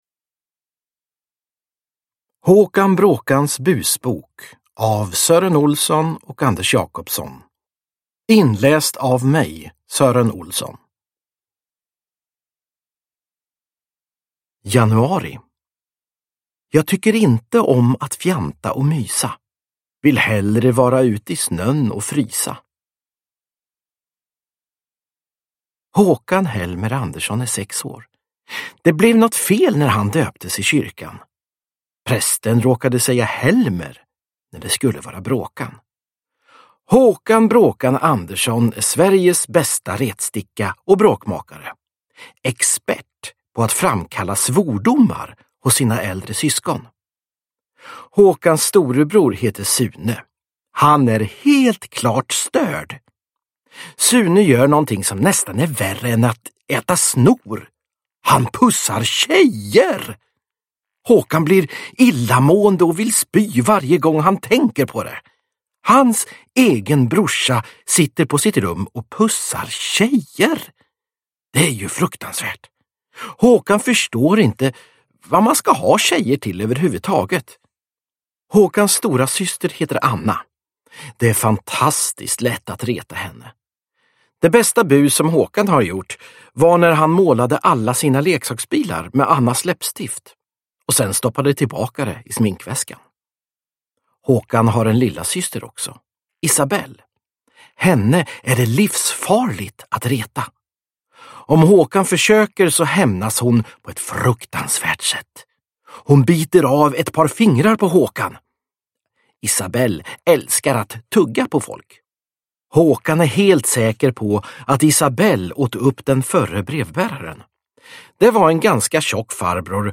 Håkan Bråkans Busbok – Ljudbok – Laddas ner
Uppläsare: Sören Olsson